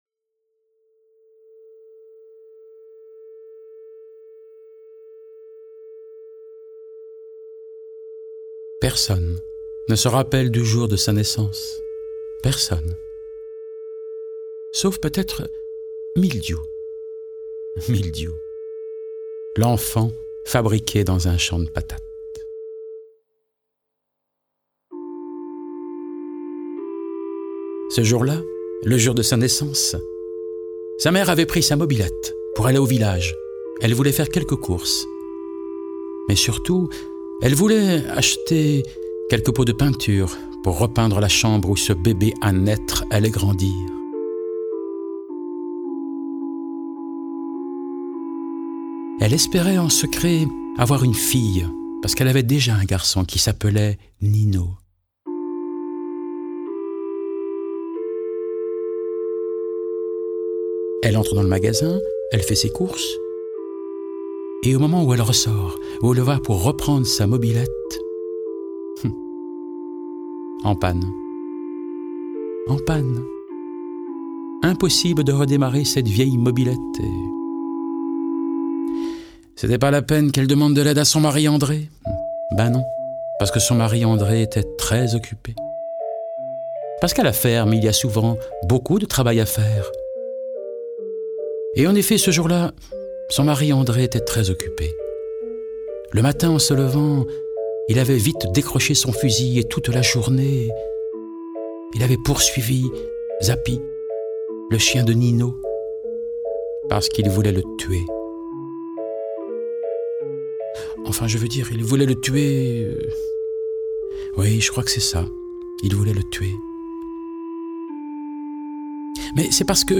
Contes d’auteurs Paru le